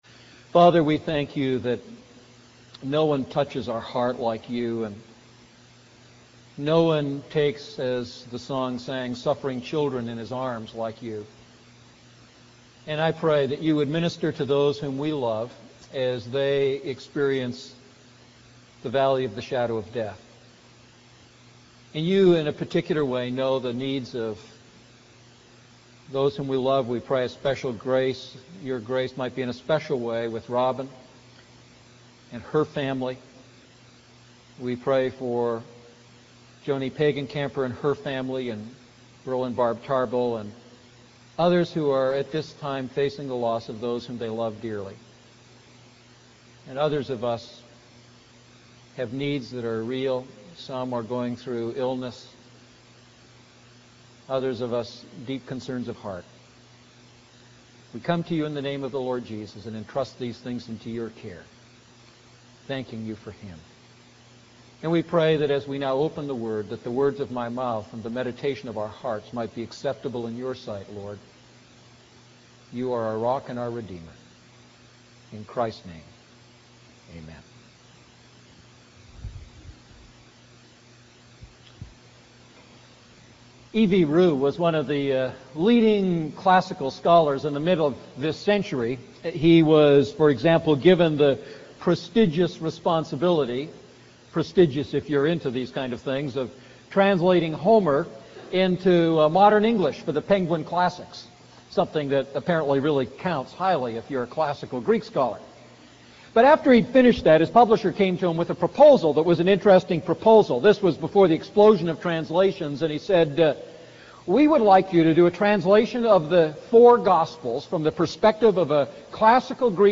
A message from the series "Luke Series I."